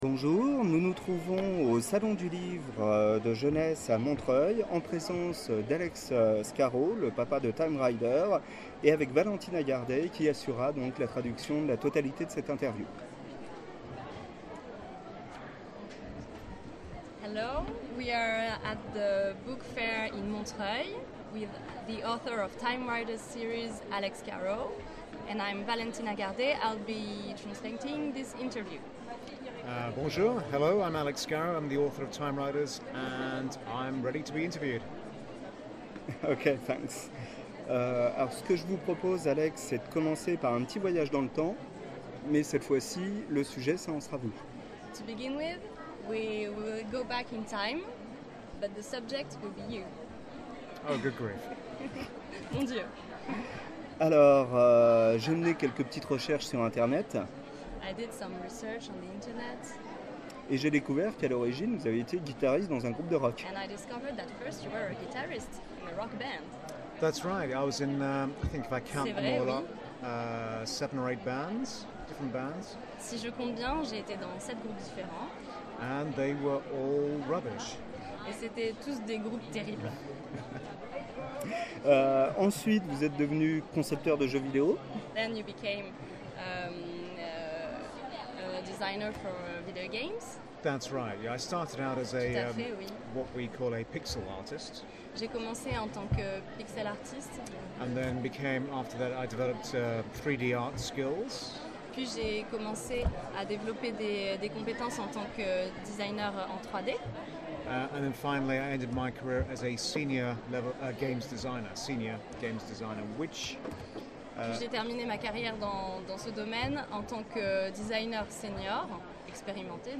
Interview Alex Scarrow